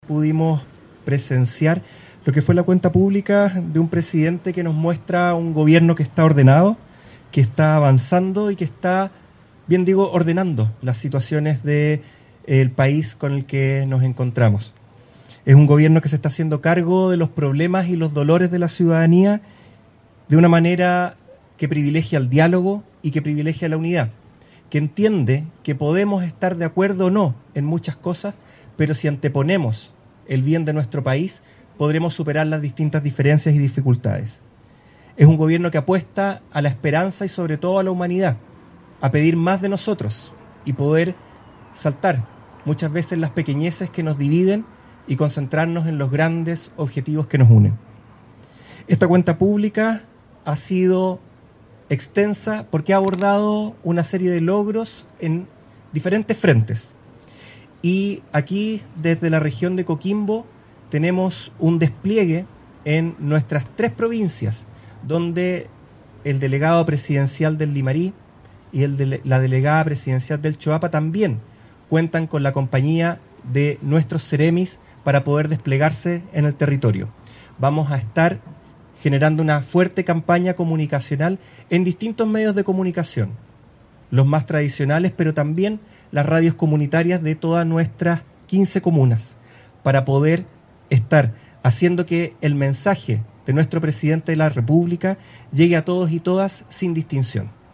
CUENTA-PRESIDENCIAL-Delegado-Presidencial-Ruben-Quezada-1-1.mp3